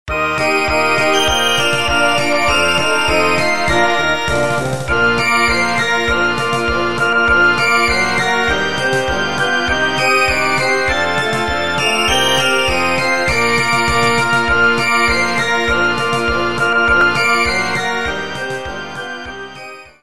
Utwory świąteczne